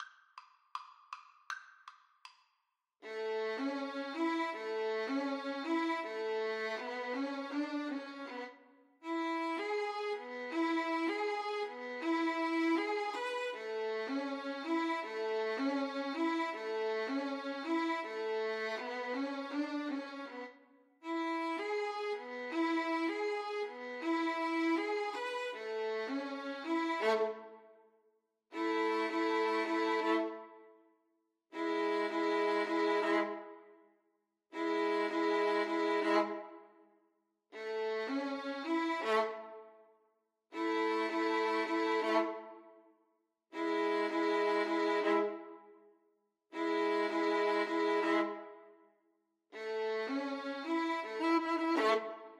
Violin 1Violin 2
Presto (View more music marked Presto)
4/4 (View more 4/4 Music)
Arrangement for Violin Duet (Two Violins)
A major (Sounding Pitch) (View more A major Music for Violin Duet )
World (View more World Violin Duet Music)